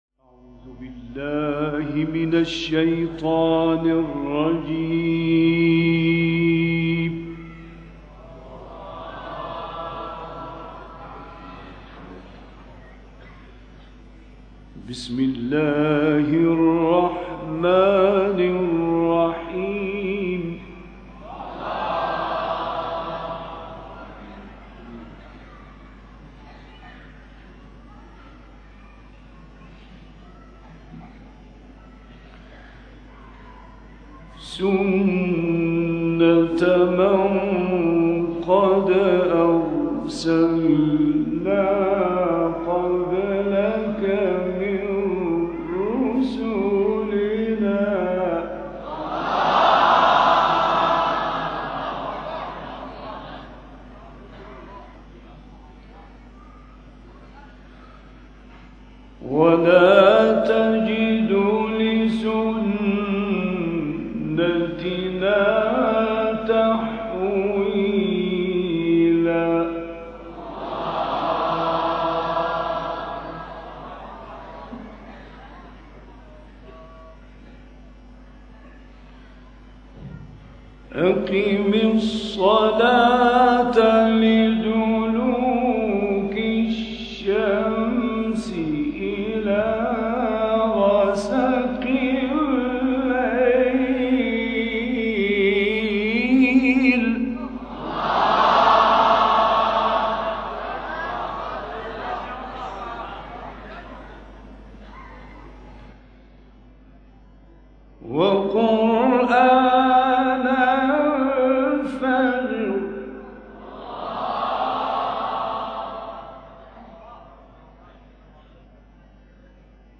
سوره : اسراء آیه: 77-81 استاد : راغب مصطفی غلوش مقام : مرکب خوانی(بیات * رست * سه گاه * رست) قبلی بعدی